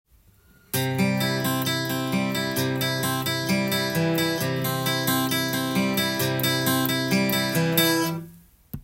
ピックと指弾き強化【ギターで16分音符のアルペジオ練習】
コードはCでアルペジオパターンを譜面にしてみました。